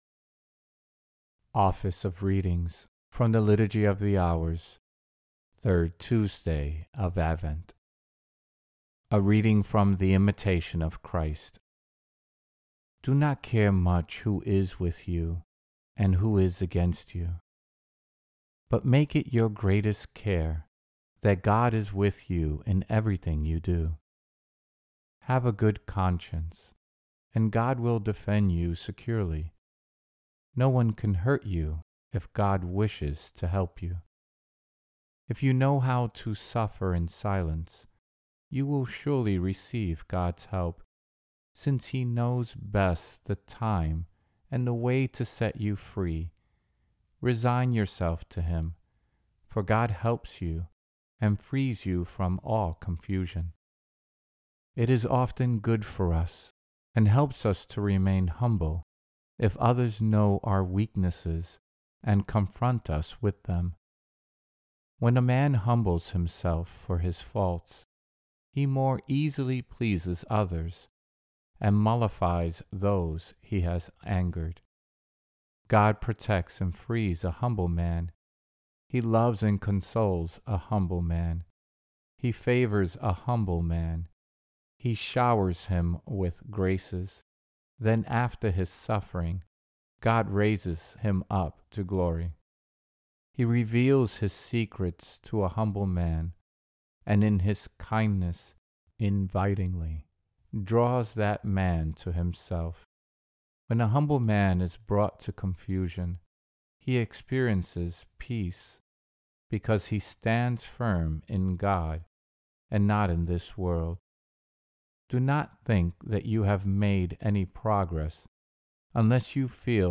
Office of Readings – 3rd Tuesday of Advent